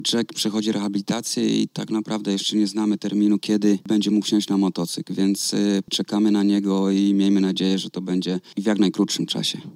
Na konferencji prasowej przed tym spotkaniem